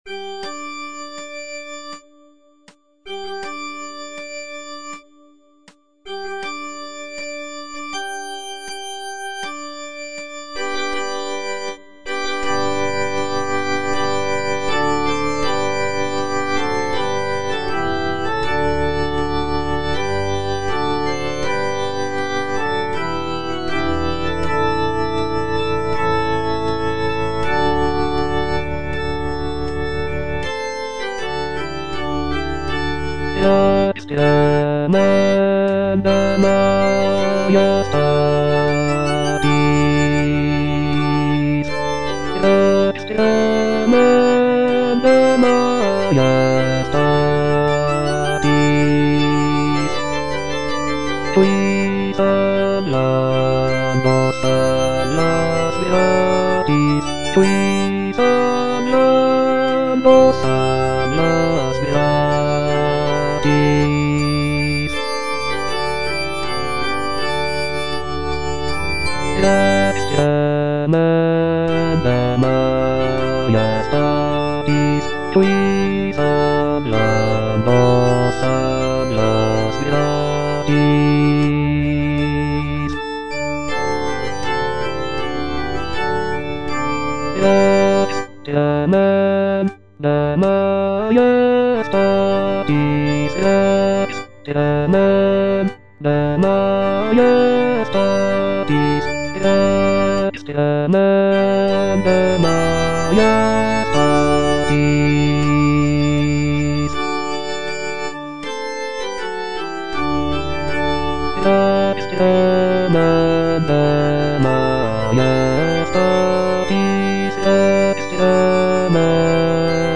bass I) (Voice with metronome
is a sacred choral work rooted in his Christian faith.